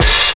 39 OP HAT 2.wav